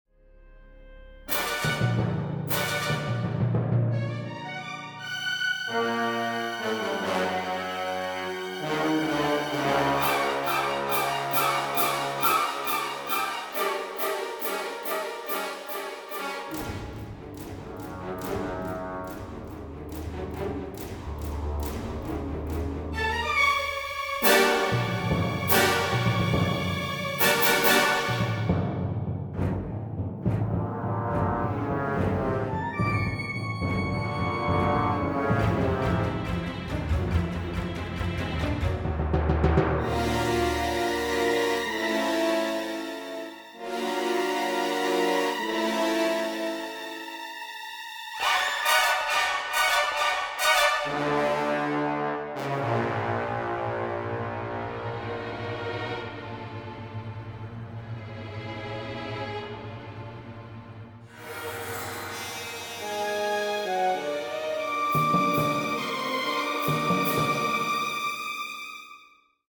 create the music using digital samplers